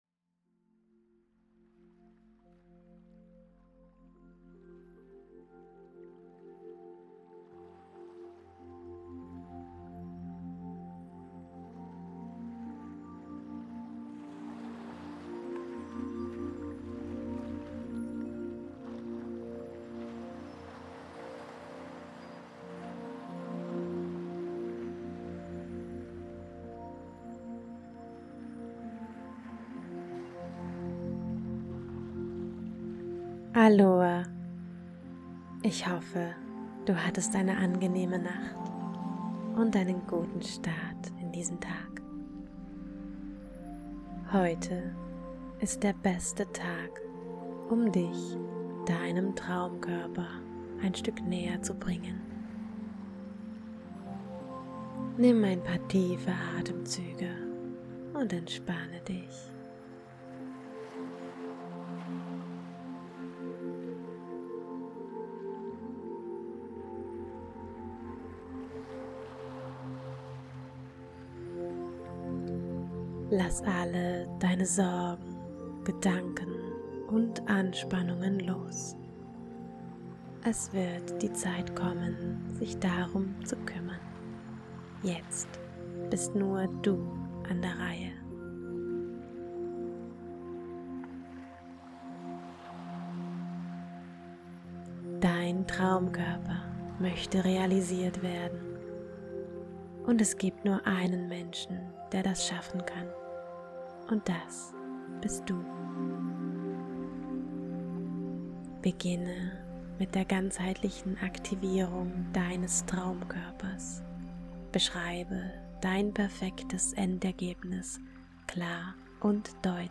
Diese Meditation ist eine Kombination aus Meditation und aktivierender Mitarbeit.
Bodytransformation Meditation